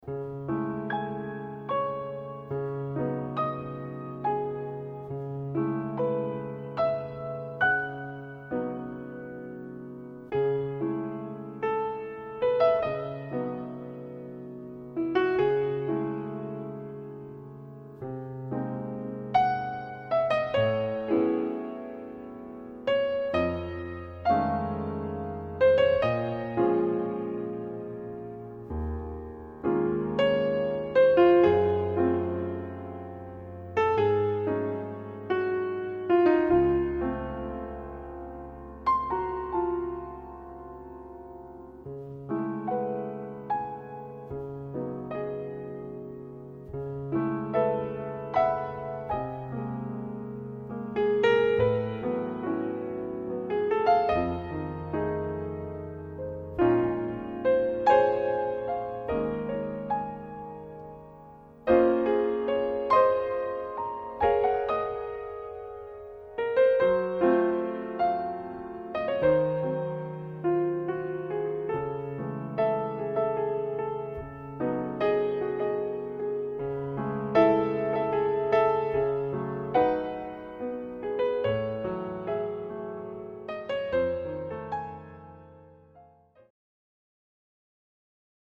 vibes, celtic harp, trumpets, clarinets, saxophones,
female vocal ensemble, and male voice.)